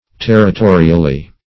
Meaning of territorially. territorially synonyms, pronunciation, spelling and more from Free Dictionary.
Search Result for " territorially" : Wordnet 3.0 ADVERB (1) 1. with respect to territory ; - Example: "territorially important" The Collaborative International Dictionary of English v.0.48: Territorially \Ter`ri*to"ri*al*ly\, adv. In regard to territory; by means of territory.